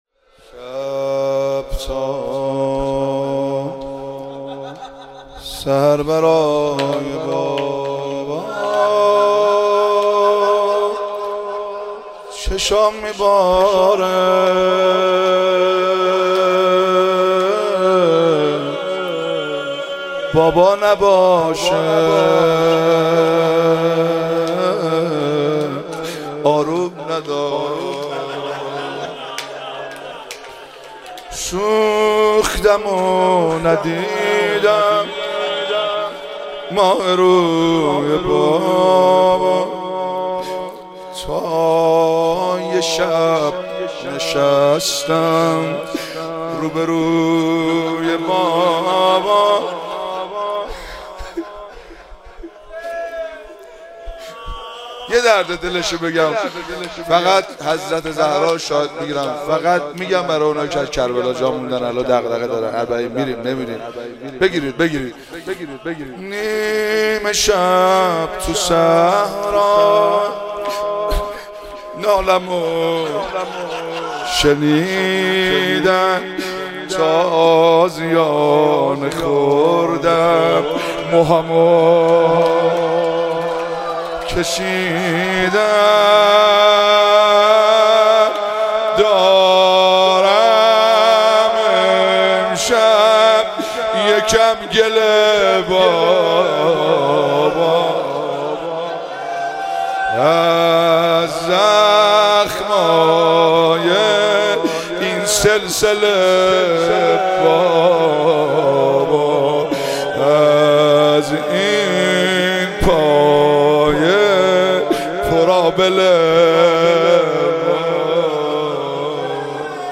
مراسم روضه اسارت اهل بیت (ع)- مرداد 1401